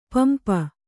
♪ pampa